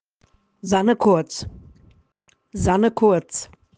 Wie spricht man deinen Namen richtig aus,